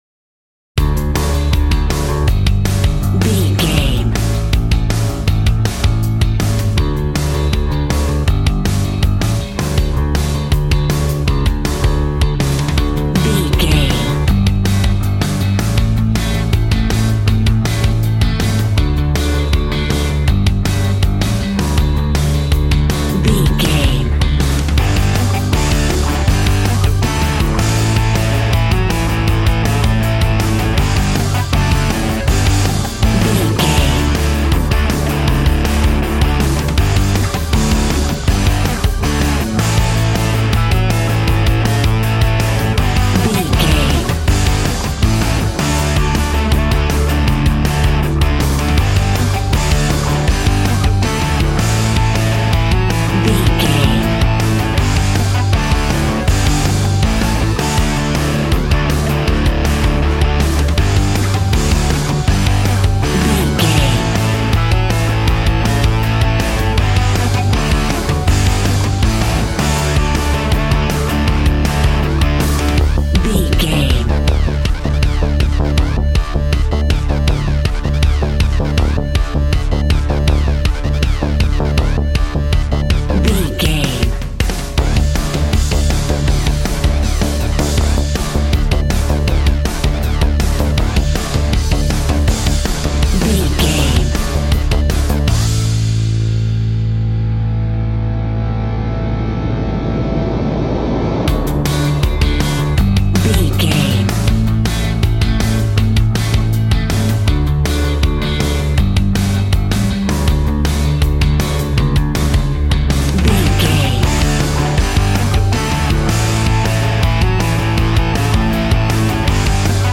Ionian/Major
groovy
happy
electric guitar
bass guitar
drums
piano
organ